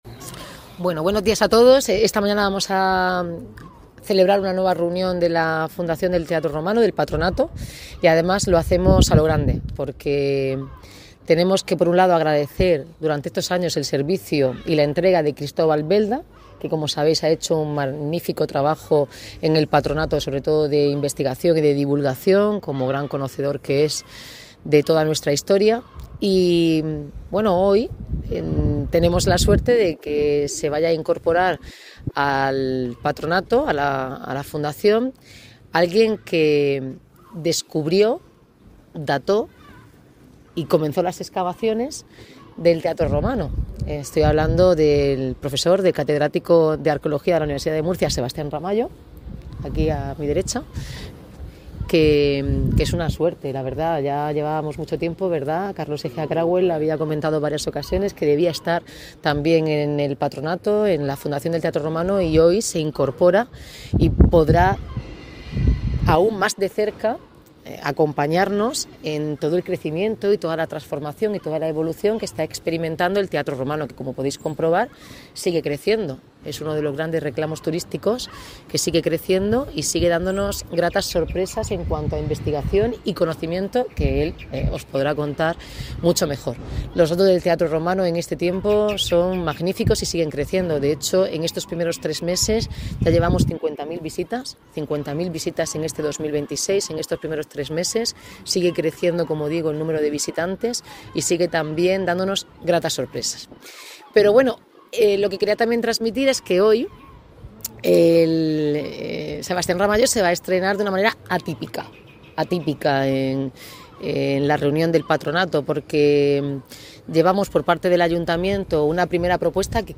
Enlace a Declaraciones de Noelia Arroyo
reunión Fundación Teatro Romano